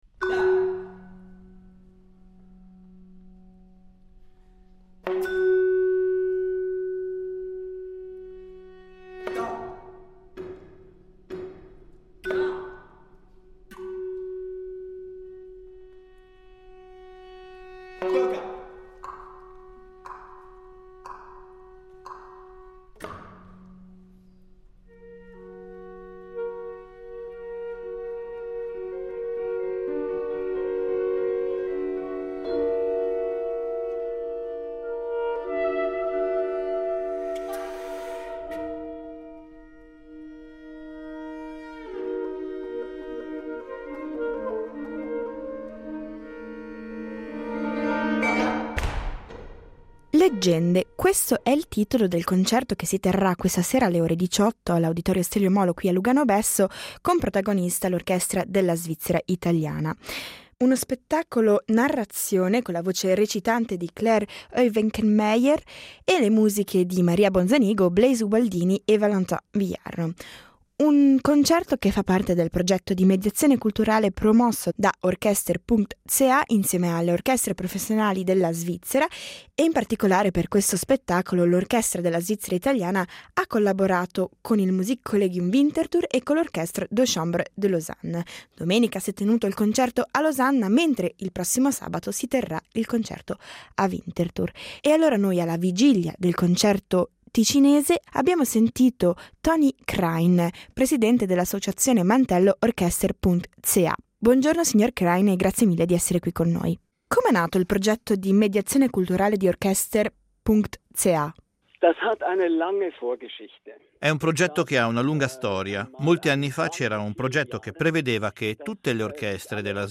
Parole e musica per raccontare la Svizzera